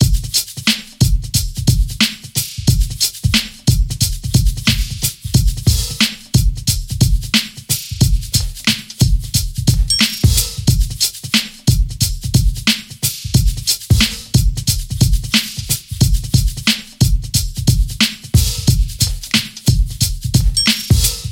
描述：用Fruity Loops制作。嘻哈乐。忙碌的鼓声循环，有摇摆器、开放的hihats、手指扣和铃铛。
Tag: 90 bpm Breakbeat Loops Drum Loops 3.59 MB wav Key : Unknown